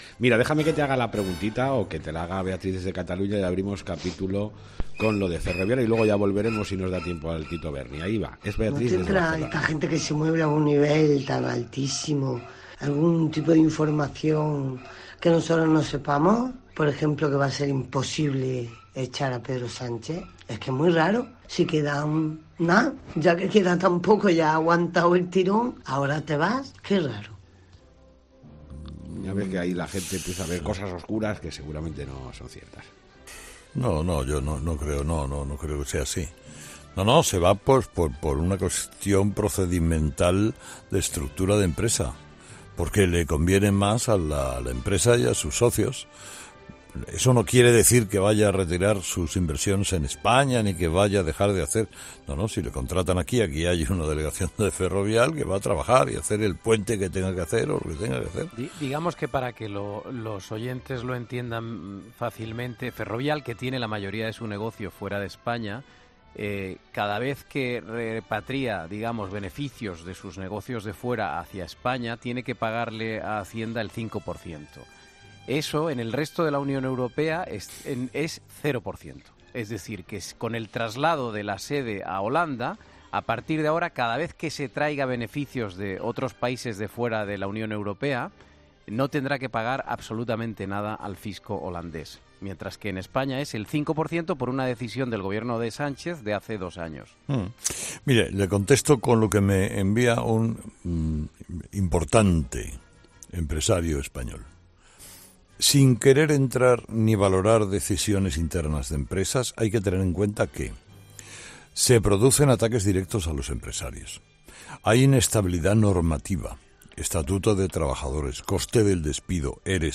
El comunicador ha compartido, en directo, el mensaje que ha recibido tras el cambio de sede social de la multinacional